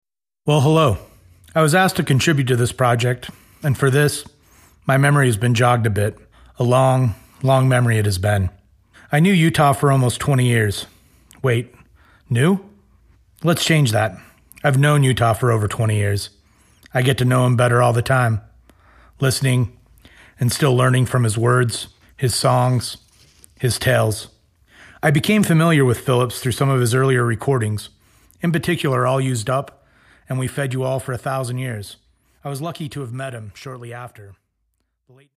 The Long Memory (spoken)